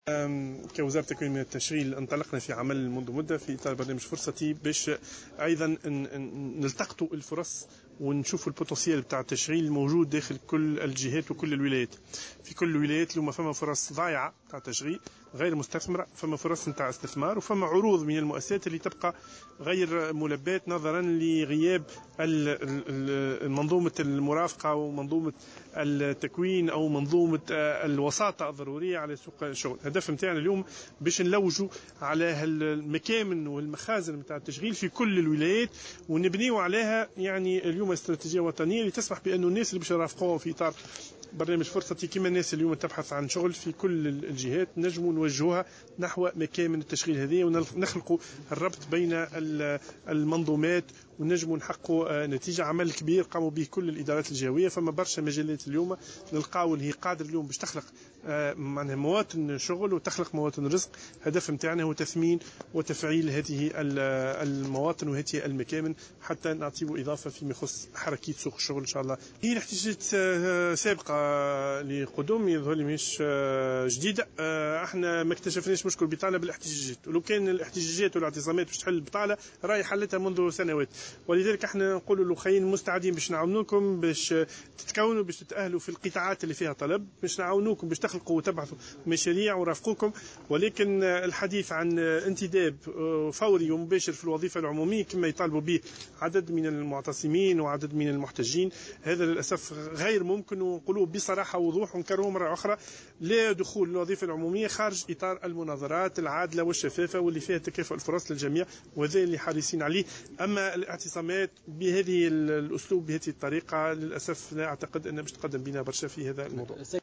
Le ministre de l'Emploi et de la Formation professionnelle, Zied Laâdhari, a réagi, ce jeudi 21 avril 2016 dans une déclaration à Jawhara Fm, aux protestations de chômeurs à Kairouan revendiquant leur recrutement immédiat dans la fonction publique.